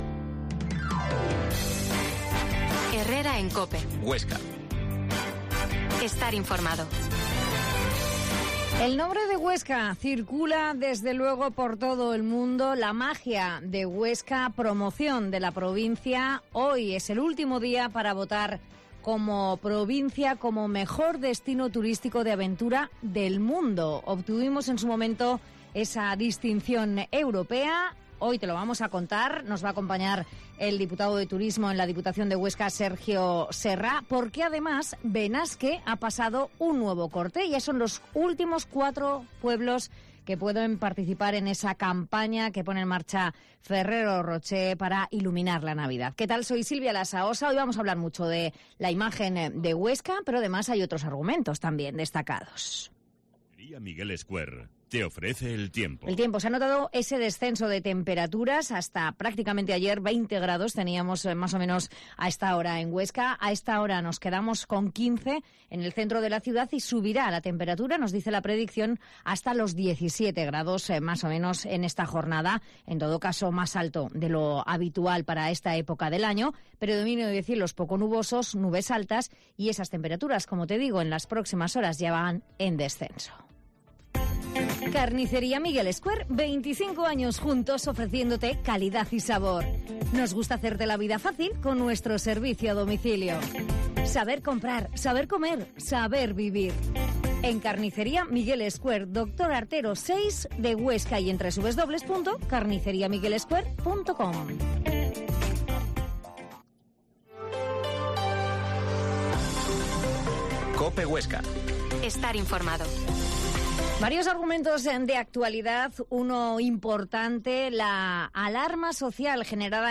Herrera en COPE Huesca 12.50h Entrevista al diputado de turismo Sergio Serra